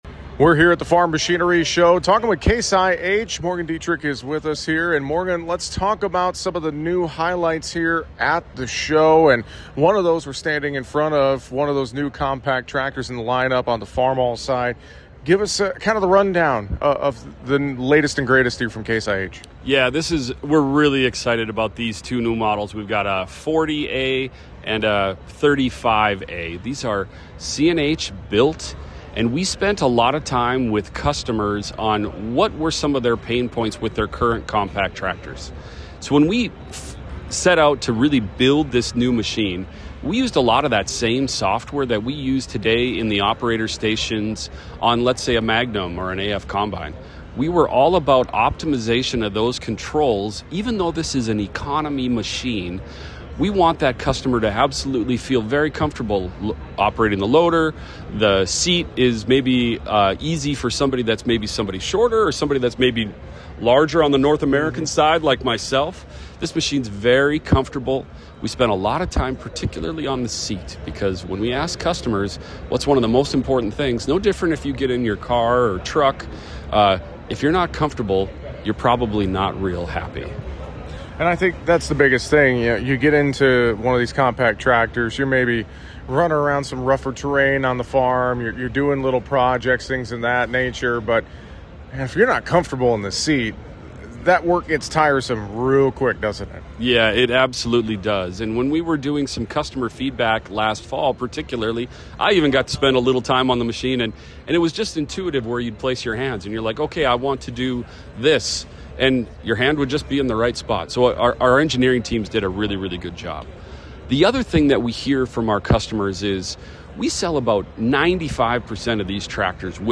joined us to tell us more at NFMS